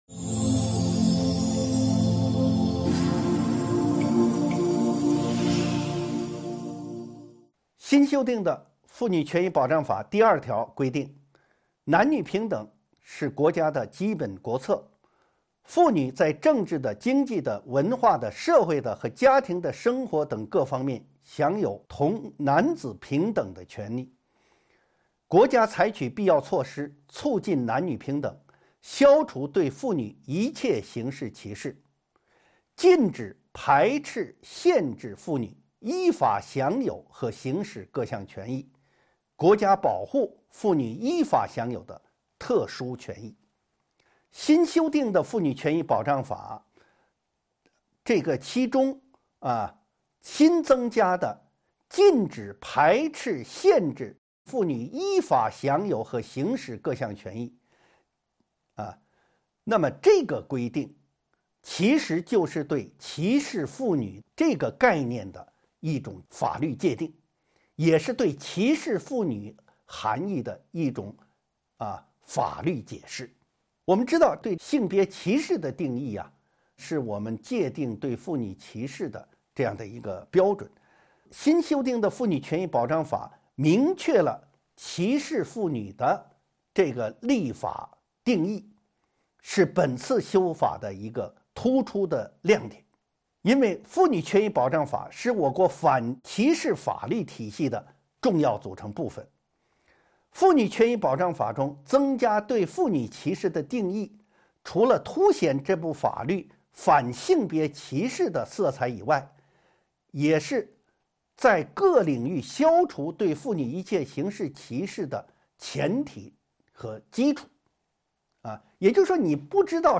音频微课：《中华人民共和国妇女权益保障法》6.对性别歧视的界定与识别